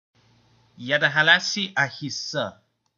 The Jadahalan Movement (Edazoran: ʆɔɒɔʌɔɂɔɛȷ ɔʌȷɿ̆; Jadahalan pronunciation:
Listeni/ˈjæˌdɑ.hɑ.lɑ.s ˈɑˌhɪ.zʌh, -zɑ/; also known as the Jadahala Party) is a Jadahalan secessionist party and the third major Edazoran political party.